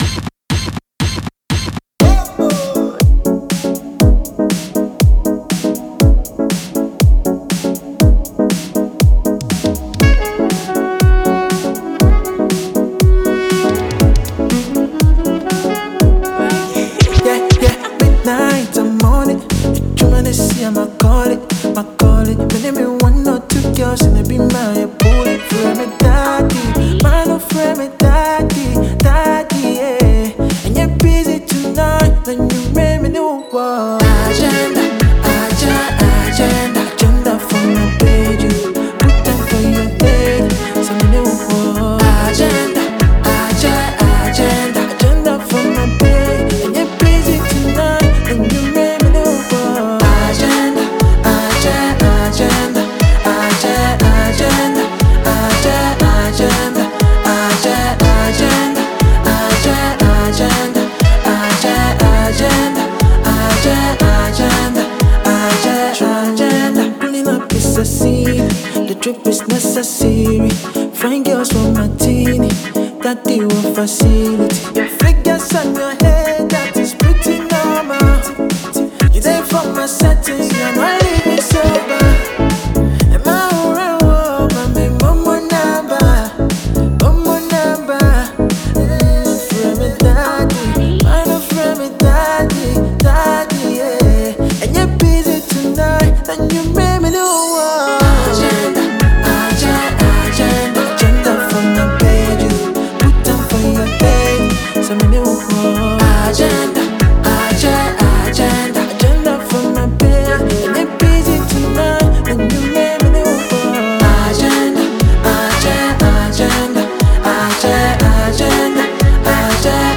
Afrofusion
Ghanaian singer